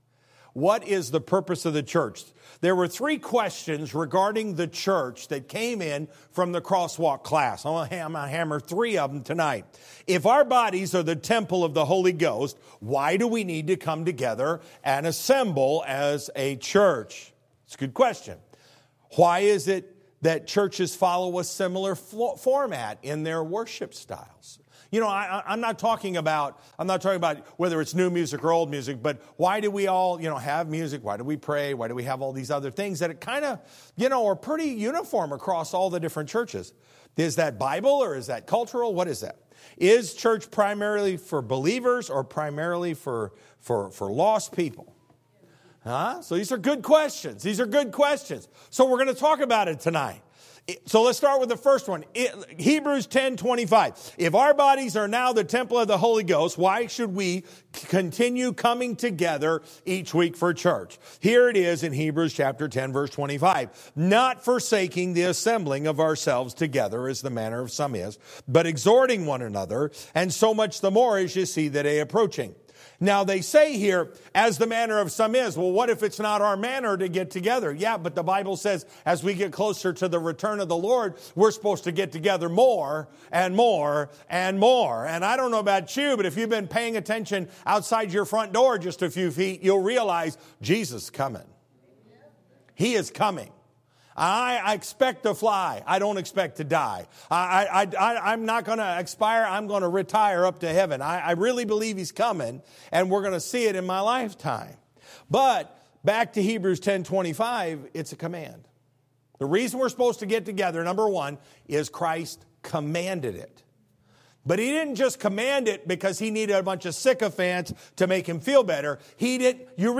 Sermon Audio :: First Baptist Church of Kingstowne